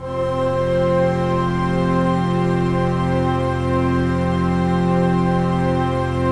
TRANCPAD02-LR.wav